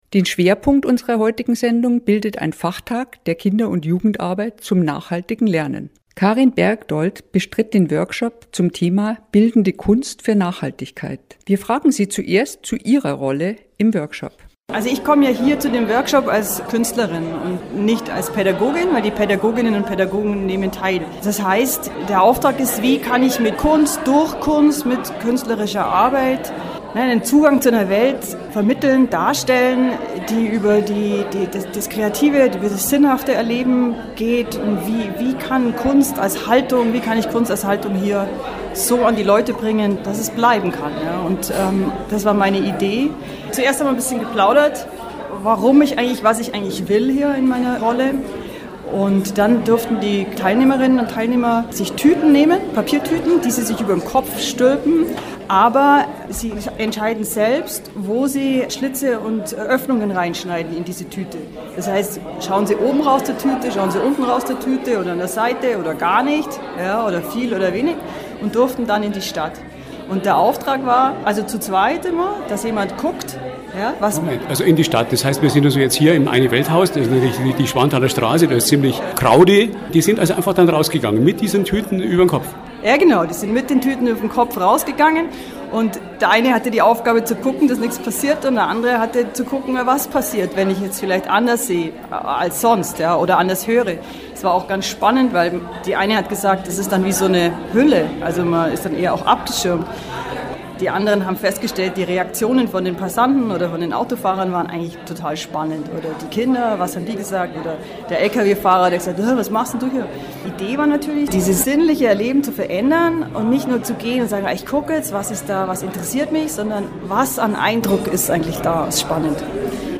Ein Radiobeitrag